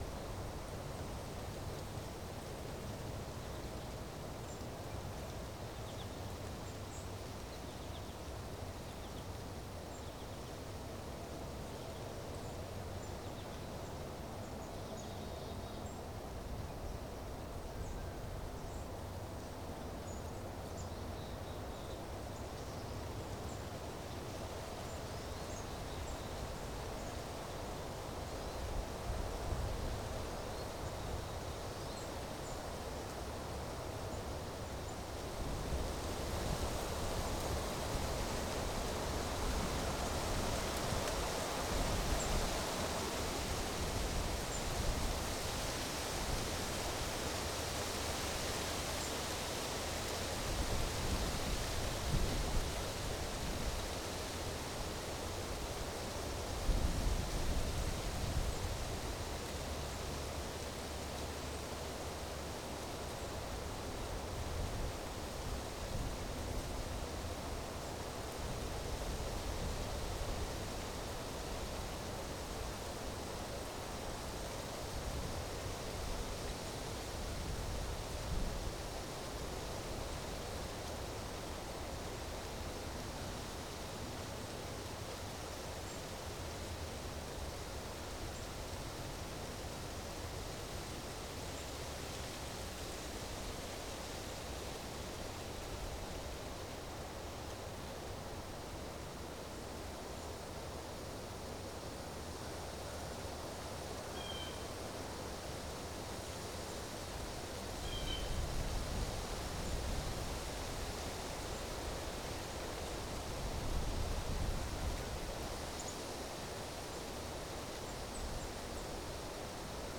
Wind In Trees Gentle Gusts.wav